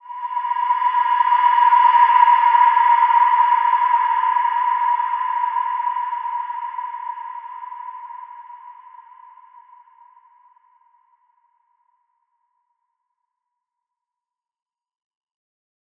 Large-Space-B5-f.wav